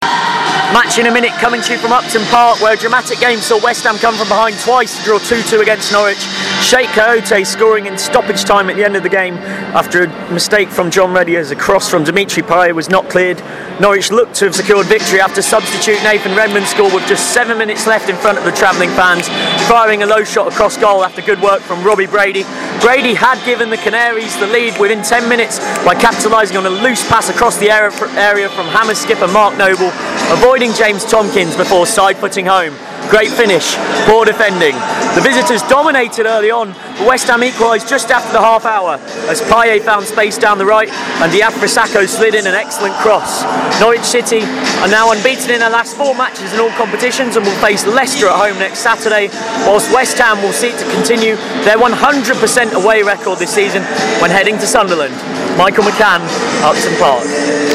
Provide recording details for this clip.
Match in a minute coming to you from Upton Park where a dramatic game saw West Ham came from behind twice to draw 2-2 against Norwich City. Chiekhou Kayoute capitalised on an error from John Ruddy in stoppage time to pounce after a cross from Dimitri Payet was not cleared.